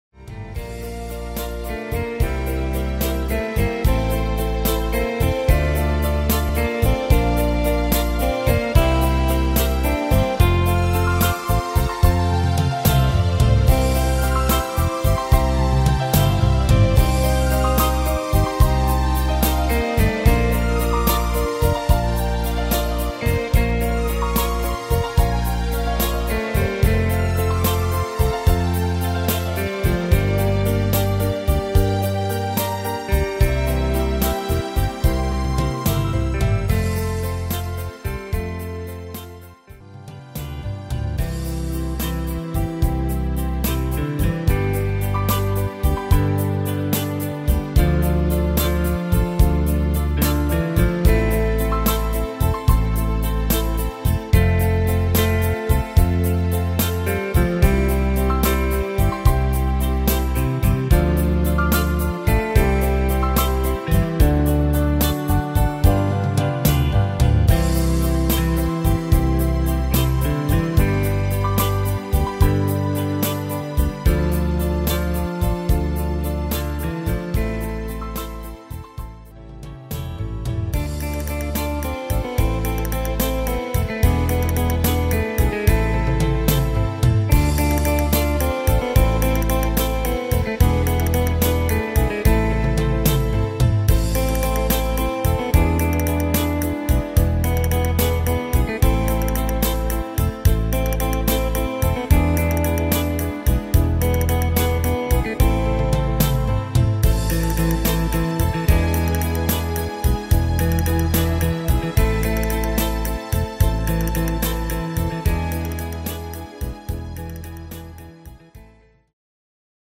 Tempo: 73 / Tonart: F-Dur